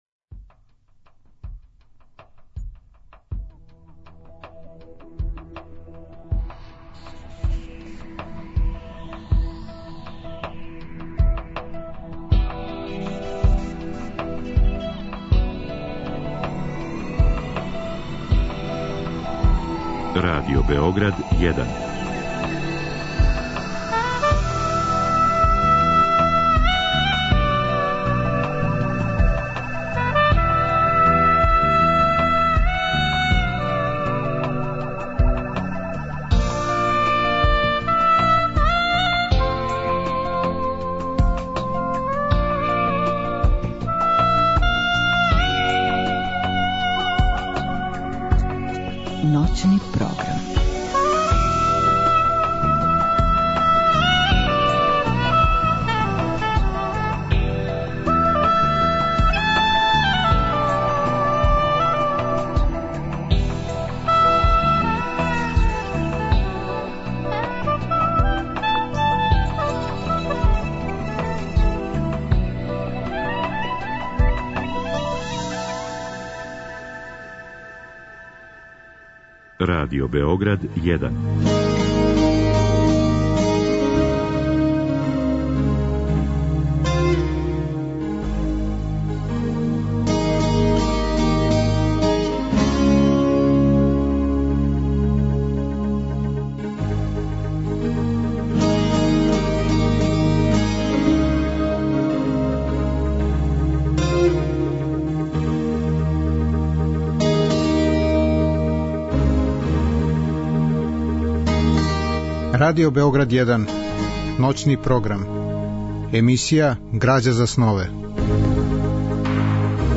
Разговор и добра музика требало би да кроз ову емисију и сами постану грађа за снове.
У ноћи између уторка и среде гост је Душко Новаковић, један од најзначајнијих савремених српских песника. Он у овој емисији говори о свом животу, уметничком сазревању, омиљеним песницима, и чита властите стихове.